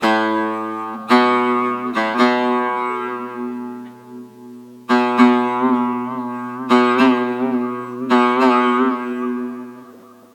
3   Transposition des instruments
Transposé par -1.5 tons sans correction
avec correction de l'enveloppe spectrale